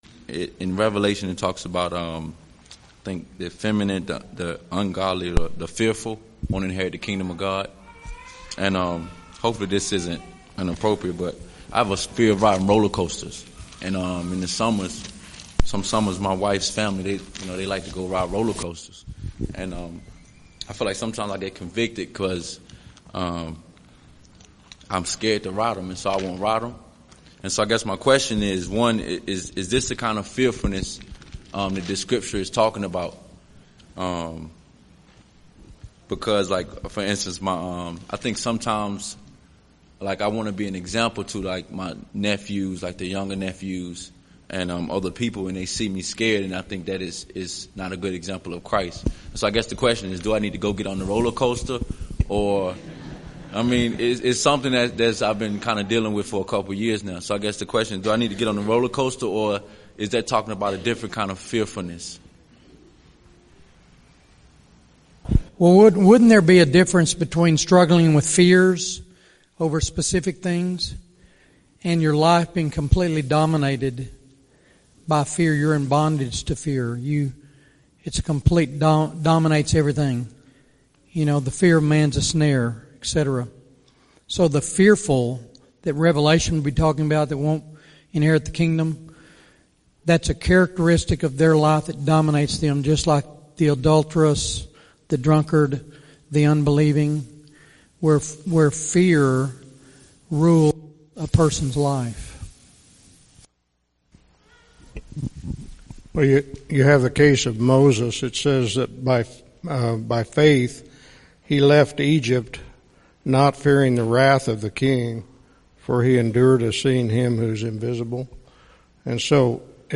| Question & Answer